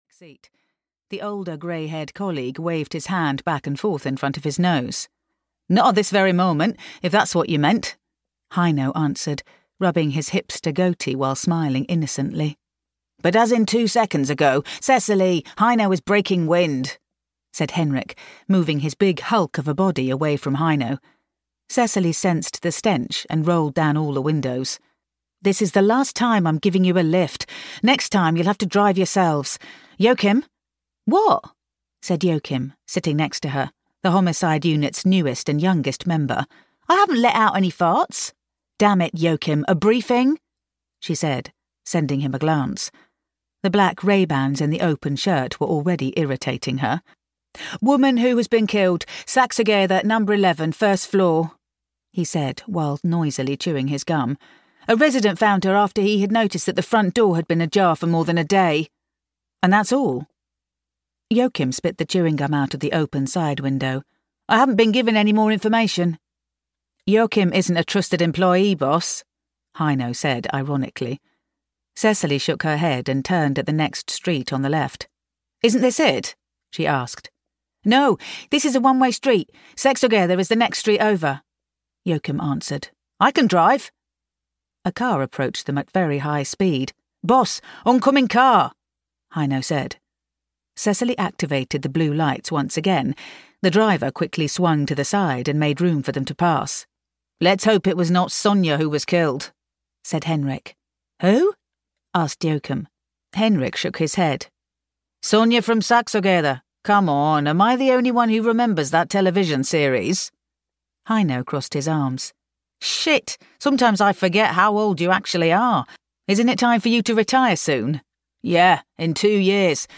Audio knihaDeath Before Dawn: An Inspector Cecilie Mars Thriller (EN)
Ukázka z knihy